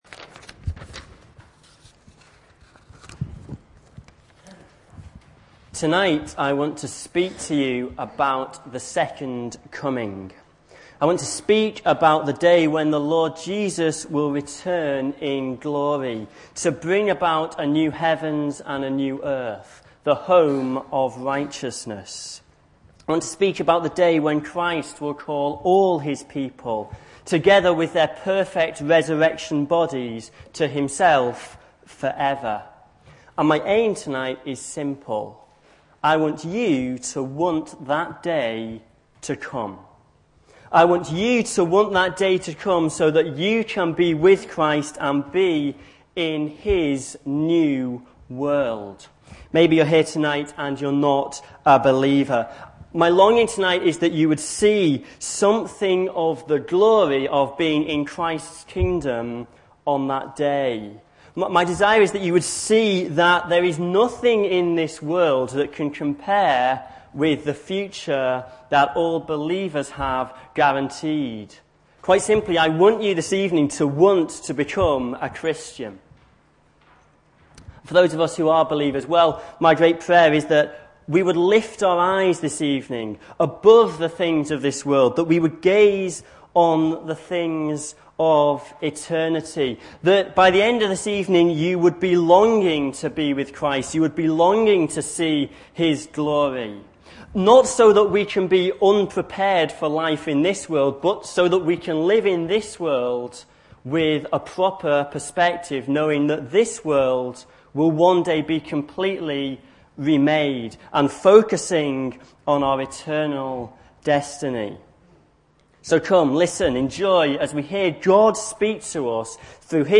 Christ's Second Coming (1 John 2:28-3:3) Recorded at Woodstock Road Baptist Church on 31 August 2008.